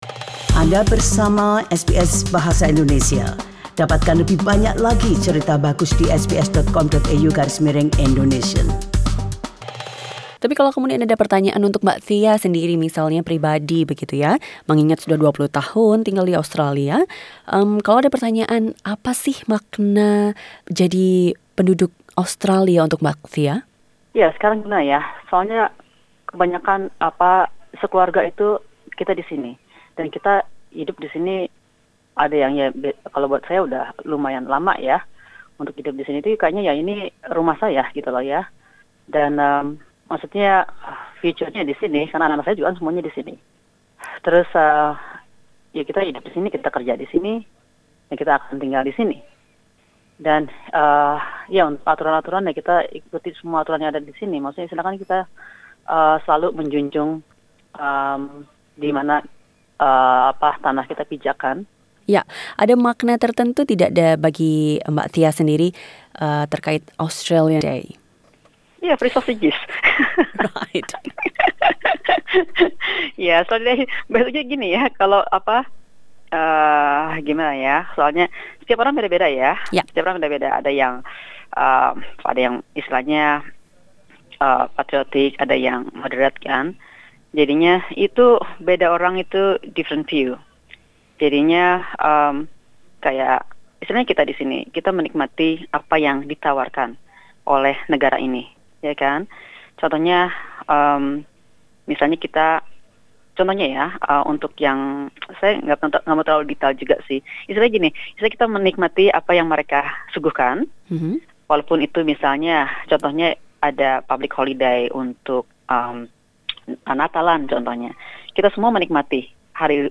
SBS Indonesian asks three Australians, with Indonesian backgrounds, about what Australia Day is to them.